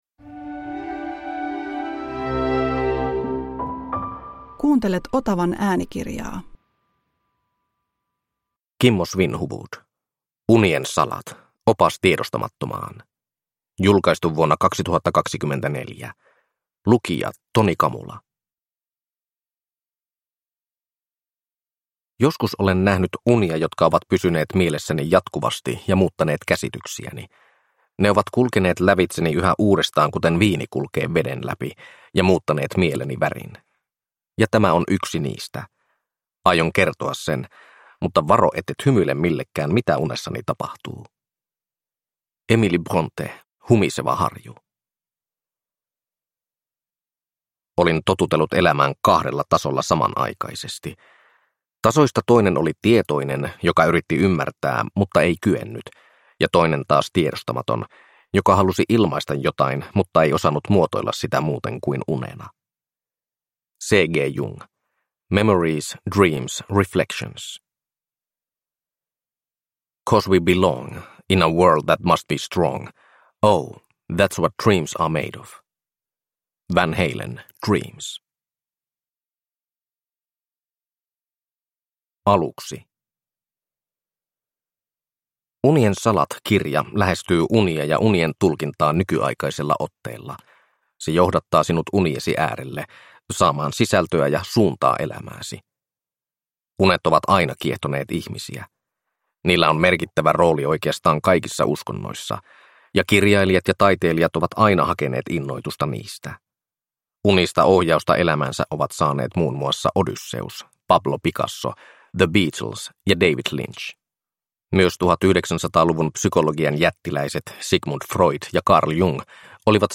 Unien salat – Ljudbok